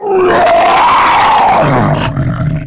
Dogadon sound effect from Donkey Kong 64
Dogadon's_Godzilla_Roar_2.oga.mp3